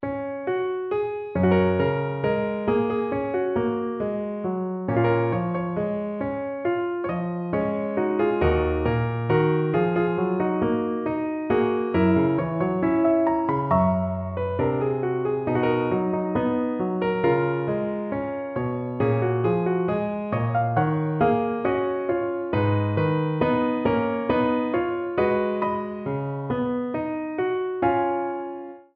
piano arr.